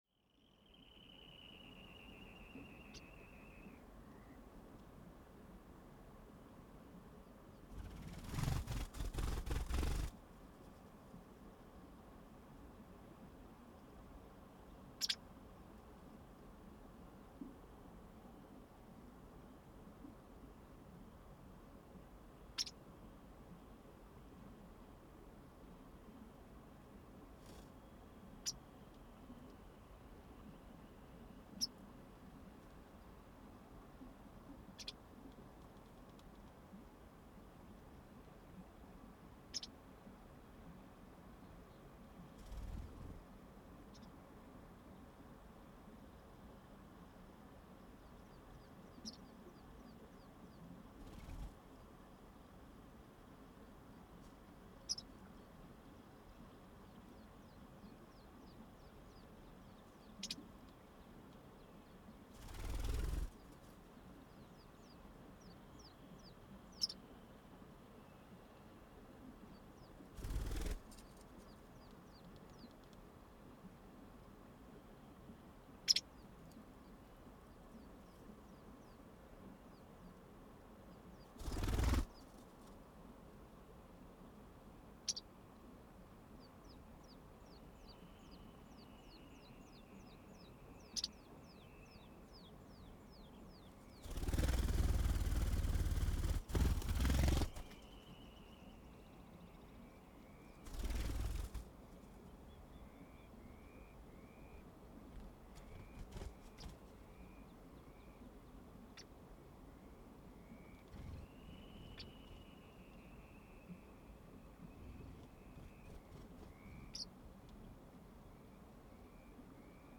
The White Wagtail in the ruins
Here is an audio recording from a secret spot in the central highlands of Iceland that I have previously shared sounds from.
It was quite windy so it was the perfect opportunity to record what was happening inside the ruins.
The recording began soon after I noticed the birds had arrived in the area.
One of the birds tried to scare the microphones away with wingbeats and warning sounds.
Later in the morning it was heard that the White Wagtail can make various sounds, probably for communication, but that will be the subject of another post.